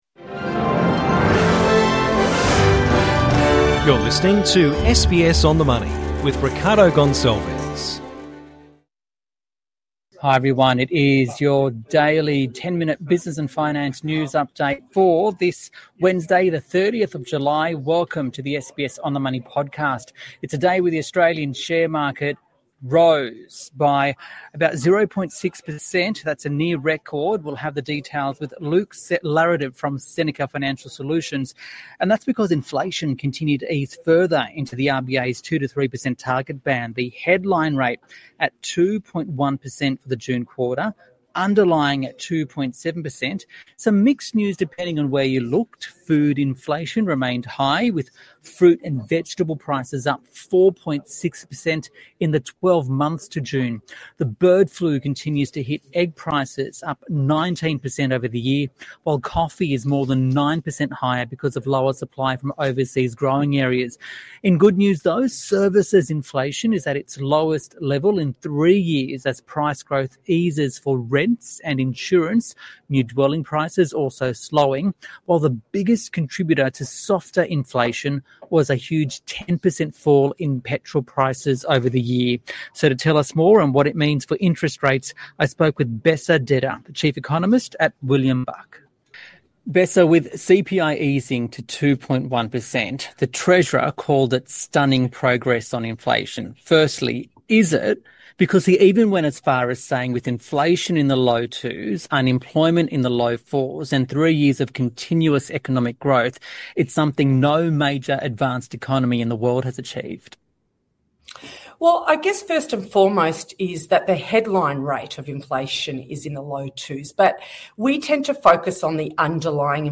it is your daily 10 minute business and finance news update for this Wednesday